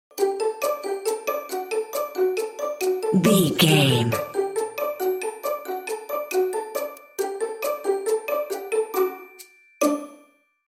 Ionian/Major
D
bouncy
cheerful/happy
lively
playful
uplifting
percussion
strings